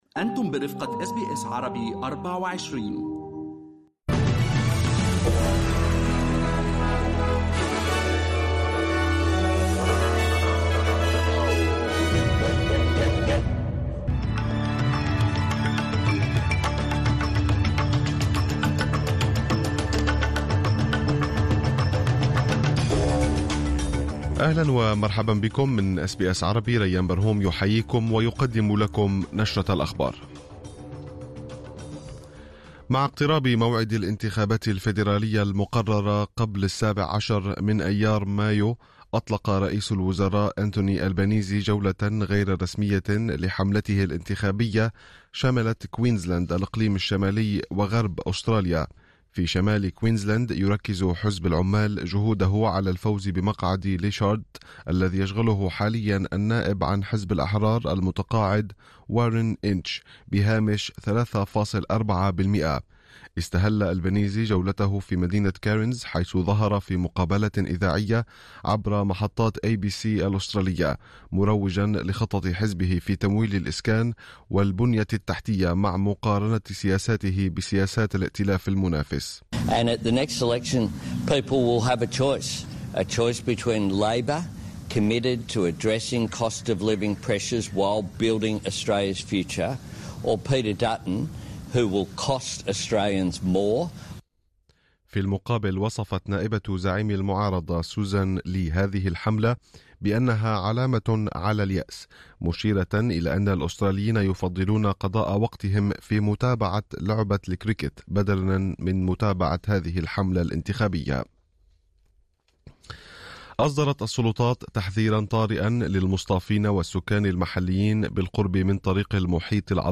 نشرة أخبار الظهيرة 08/01/2025